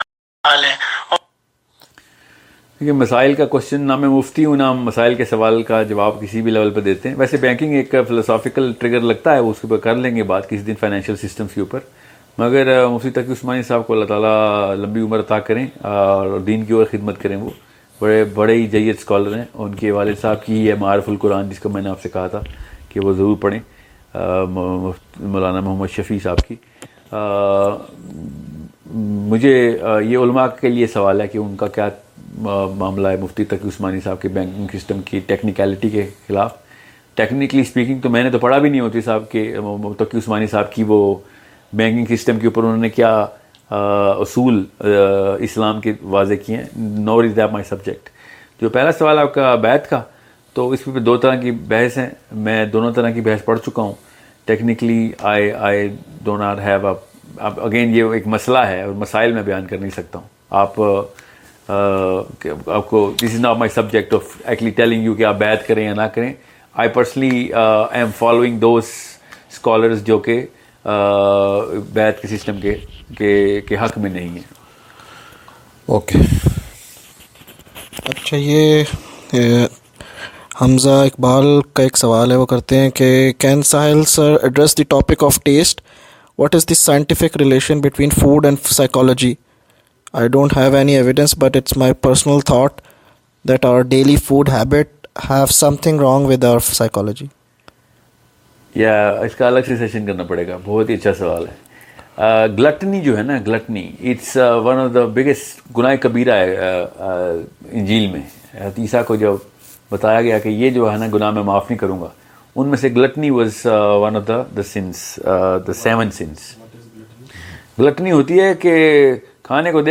Banking Mufti Taqi Usmani ｜ gluttony - QnA series.mp3